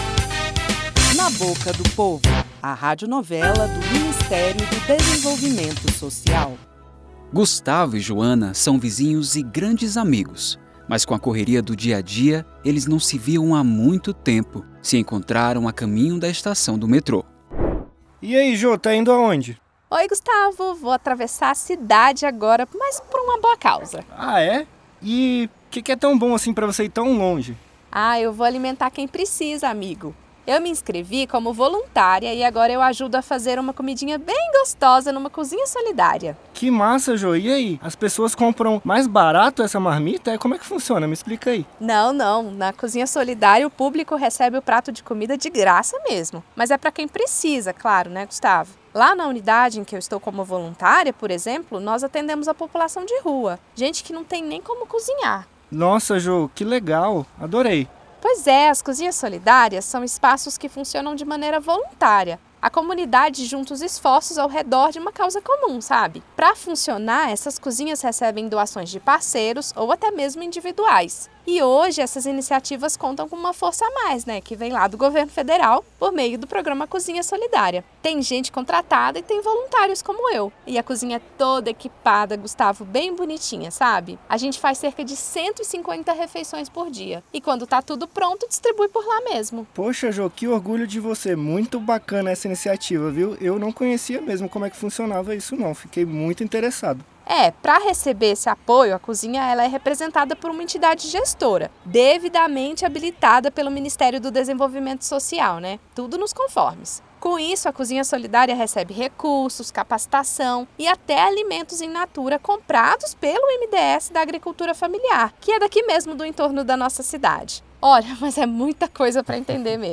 Na Boca do Povo - Radionovela